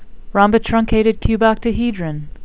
(rom-bi-trun-cat-ed   cube-oct-a-hed-ron)